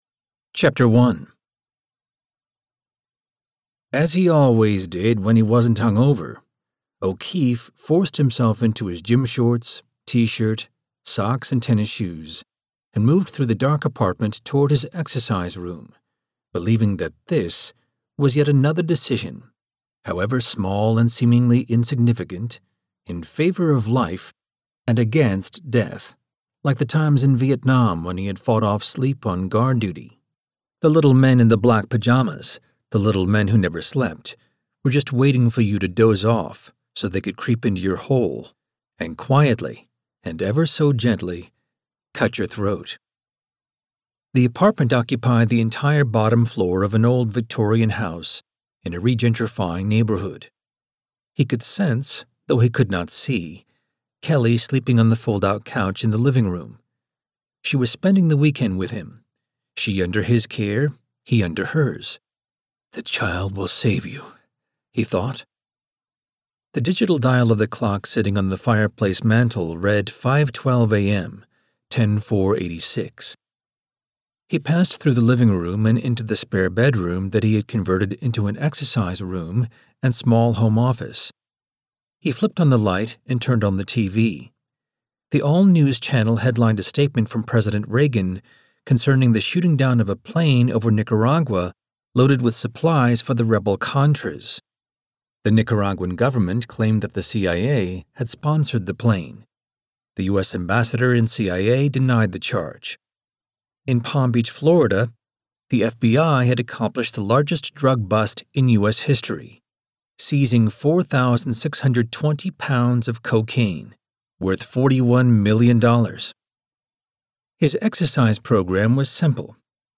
• Audiobook • 08 hrs 55 min
Genre: Mystery / Thriller / Suspense